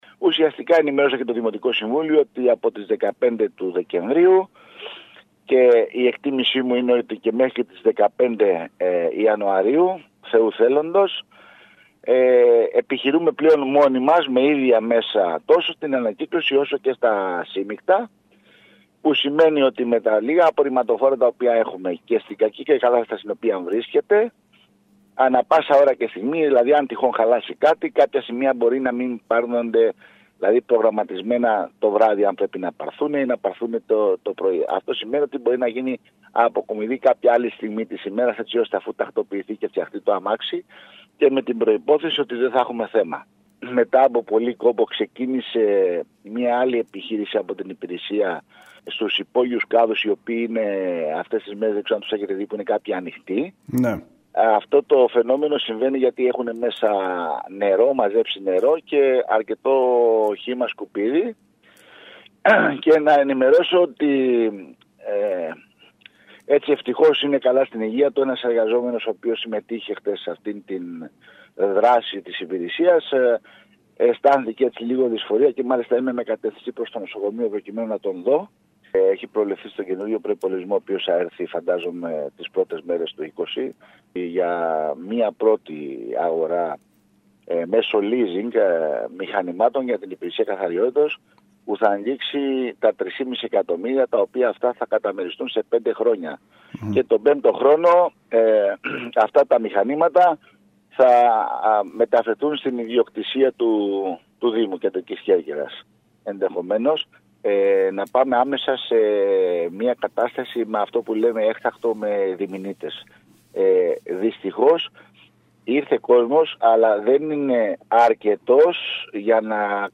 Χωρίς τη συνέργια ιδιωτών οι συμβάσεις των οποίων έχουν λήξει και με προβλήματα συντήρησης των λίγων απορριμματοφόρων που διαθέτει ο Δήμος Κεντρικής Κέρκυρας πραγματοποιείται η αποκομιδή των απορριμμάτων.  Νέα οχήματα συνολικού προϋπολογισμού 3,5 εκ ευρώ με λίζινγκ θα αποκτήσει η υπηρεσία καθαριότητας από τη νέα χρονιά, τόνισε ο αρμόδιος αντιδήμαρχος Γιάννης Σερεμέτης μιλώντας στην ΕΡΤ.